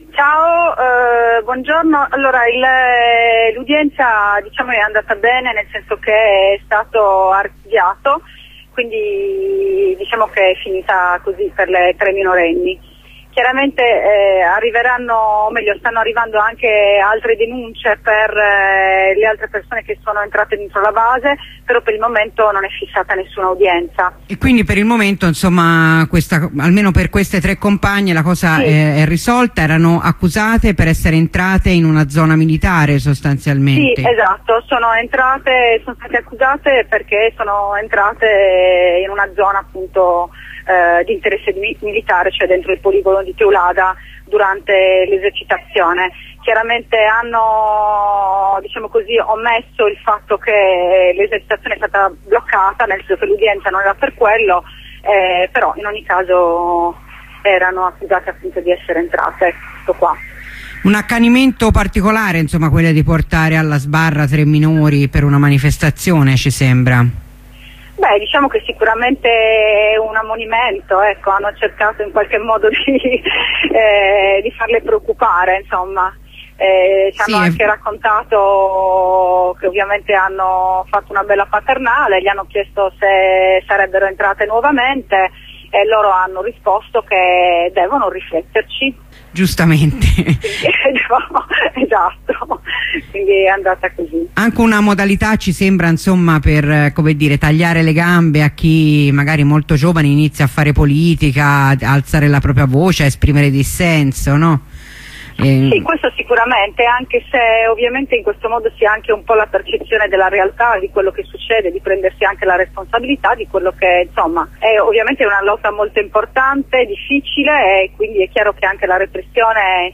Nel primo audio la corrispondenza dopo l'avvenuta archiviazione nel quale la compagna ci dà conto anche di un secondo presidio in solidarietà con un compagno in regime di 14bis.